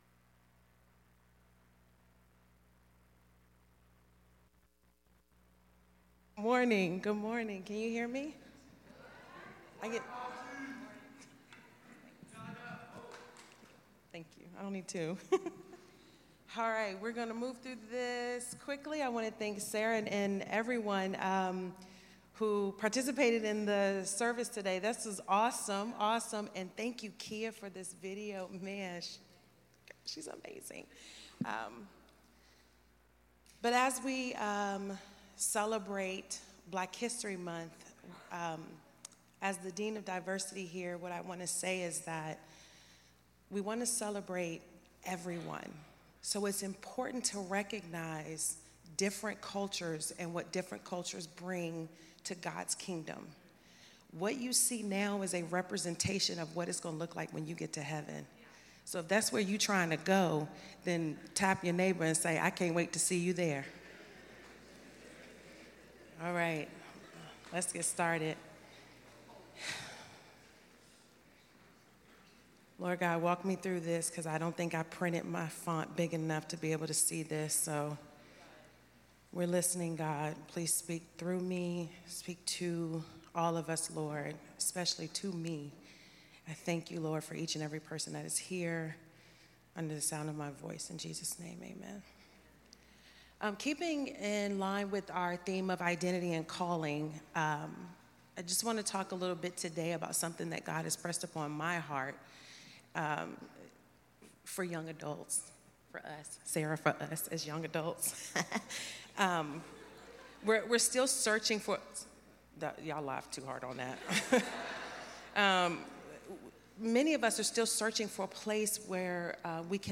This talk was given in chapel on Wednesday, February 5th, 2025.